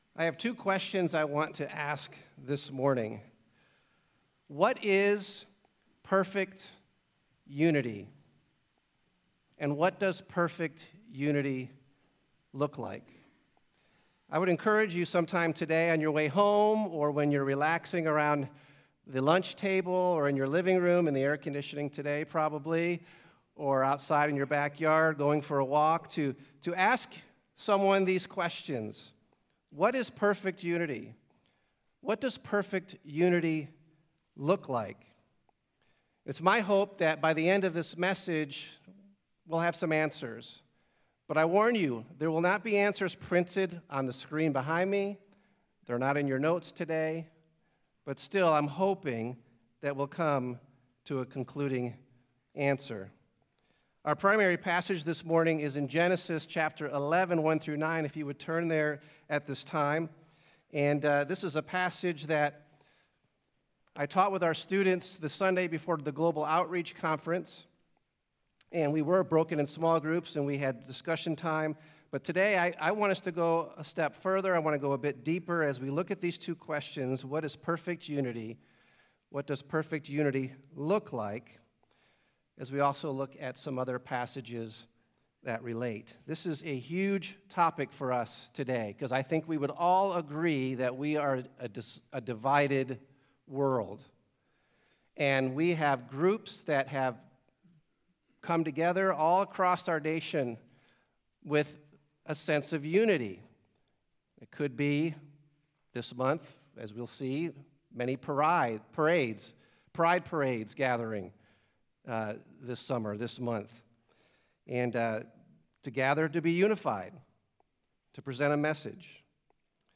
Sermons | Calvary Monument Bible Church